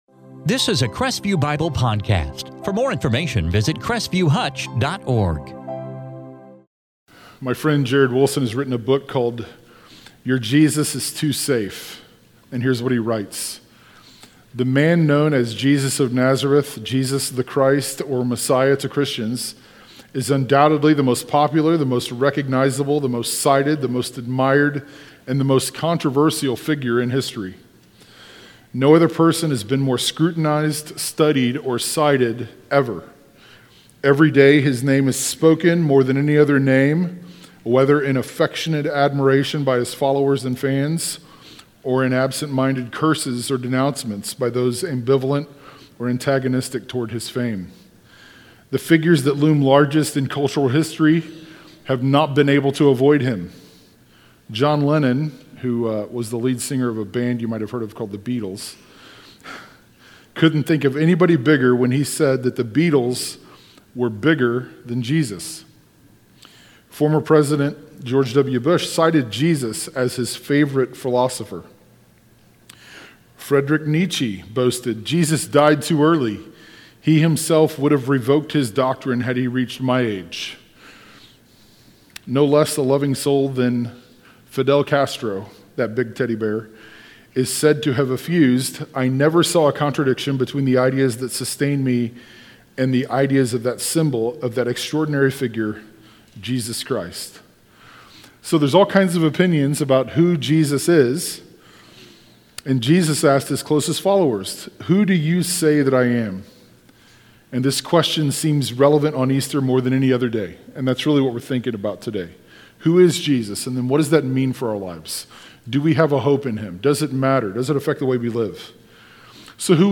Easter sermon